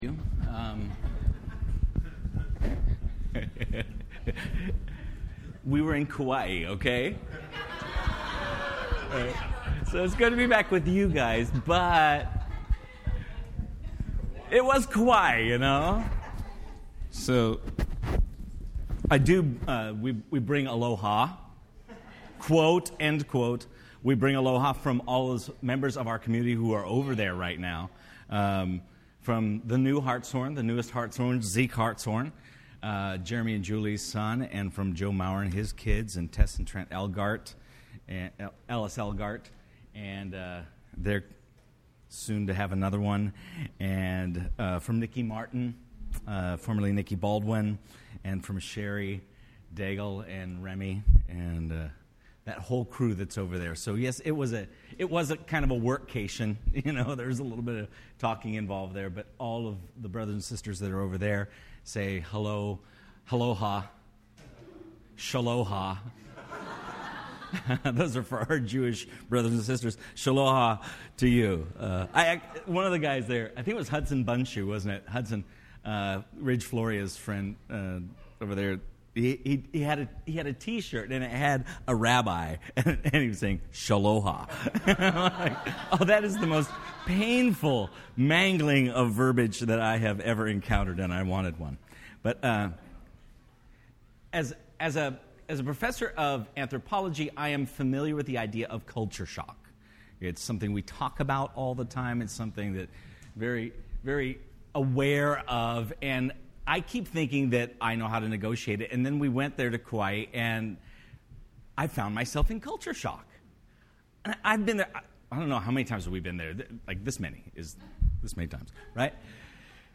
FWC Sermons Service Type: Sunday Morning %todo_render% Related « The “little” sins